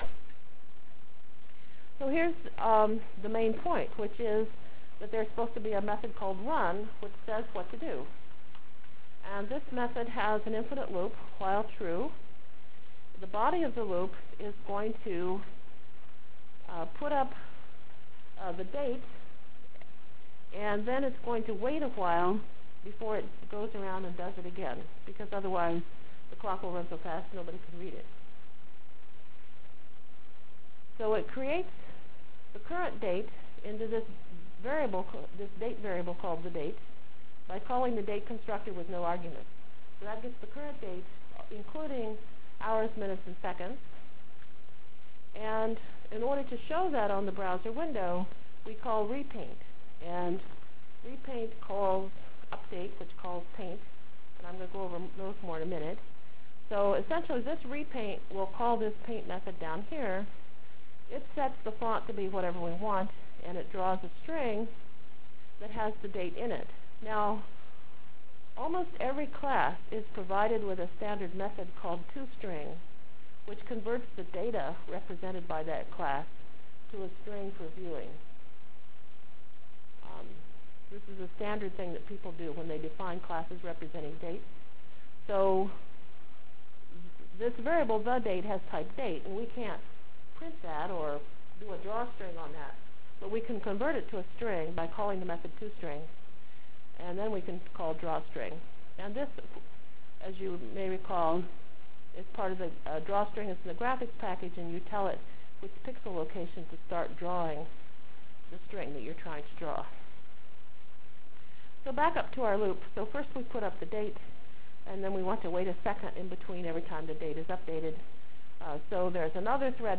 From Jan 29 Delivered Lecture for Course CPS616 -- Java Lecture 3 -- Exceptions Through Events CPS616 spring 1997 -- Jan 29 1997.